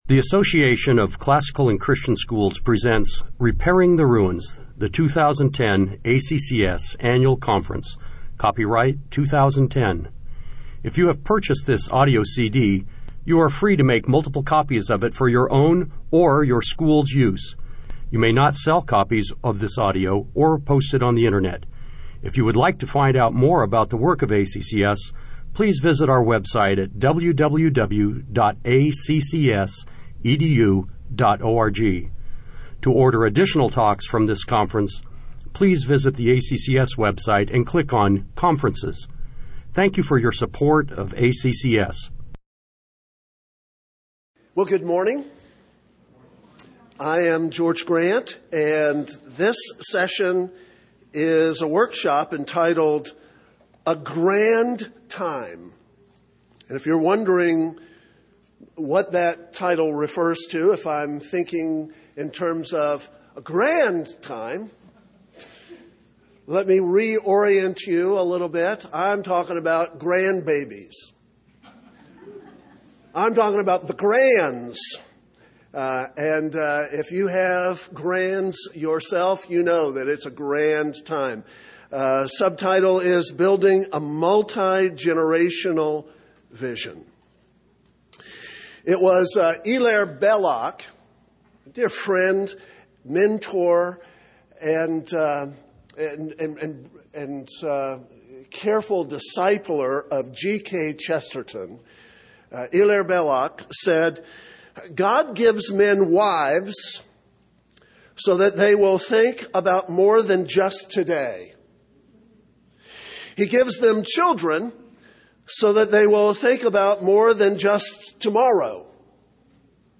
2010 Workshop Talk | 1:00:20 | Fundraising & Development, Marketing & Growth
The Association of Classical & Christian Schools presents Repairing the Ruins, the ACCS annual conference, copyright ACCS.